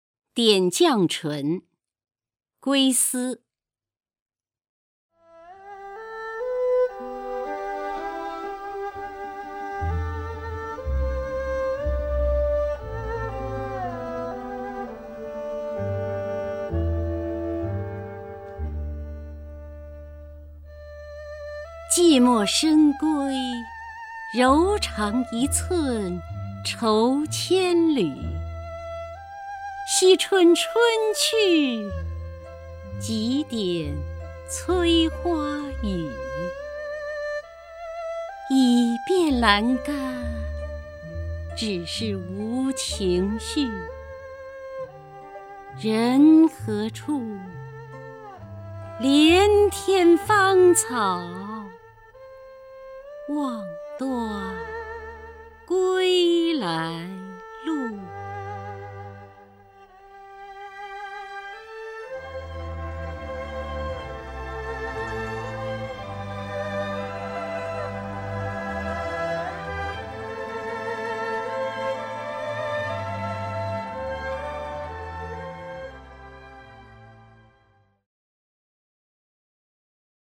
首页 视听 名家朗诵欣赏 姚锡娟
姚锡娟朗诵：《点绛唇·寂寞深闺》(（南宋）李清照)